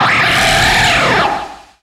Cri de Tokorico dans Pokémon Soleil et Lune.